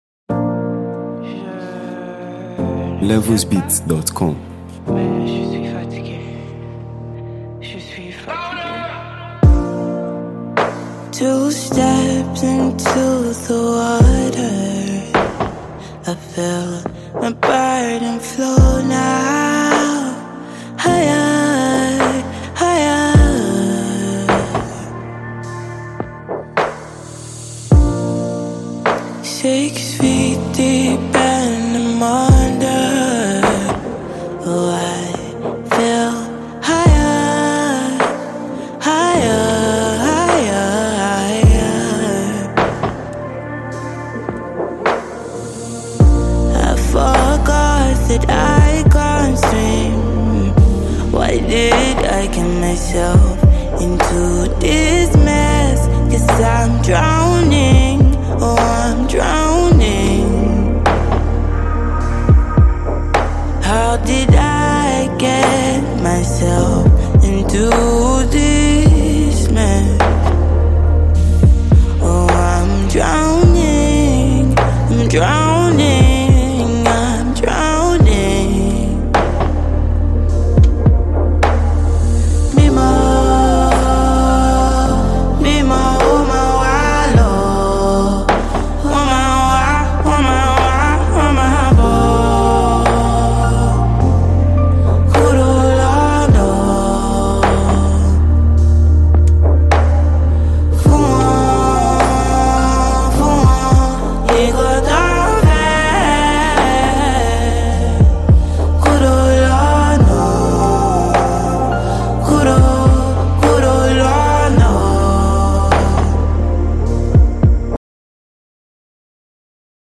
With its enchanting melodies and heartfelt delivery